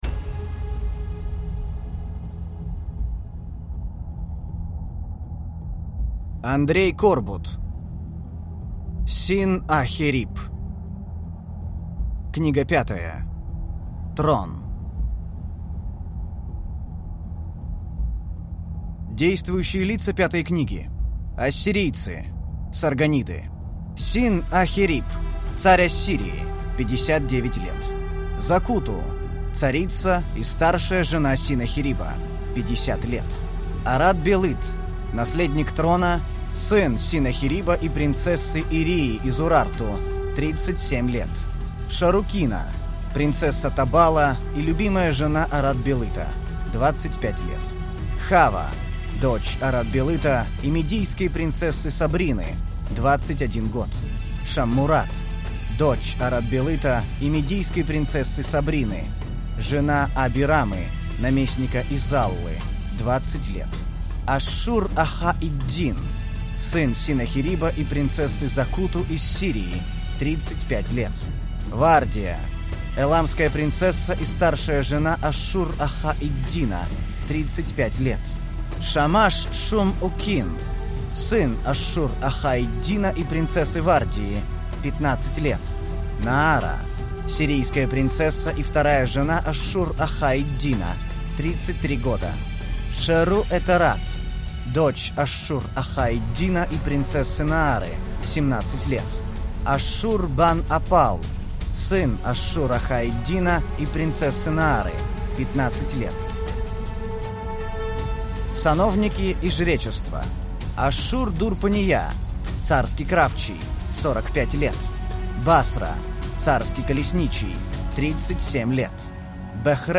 Аудиокнига Книга 5. Трон | Библиотека аудиокниг